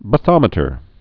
(bə-thŏmĭ-tər)